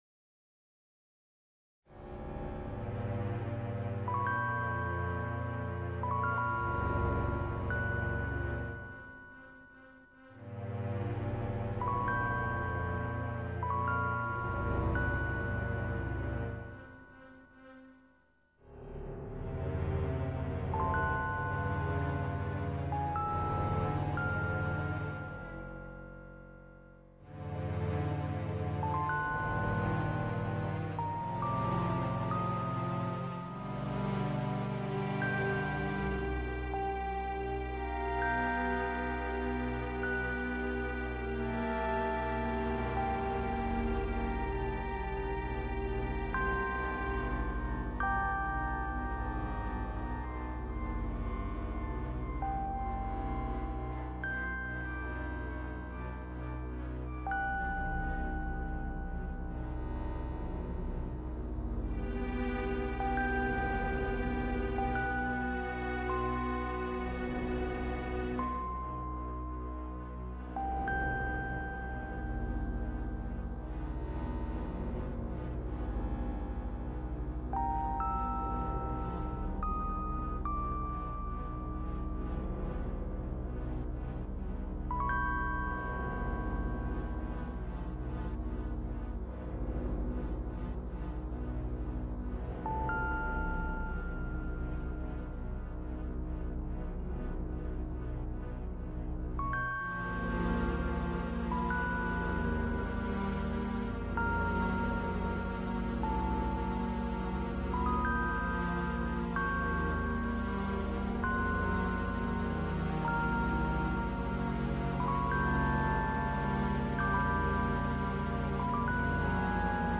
Another creepy tune of groans and creaks and tormented twisted metal, down creepy cage street next to the Petstore with cages rattling away.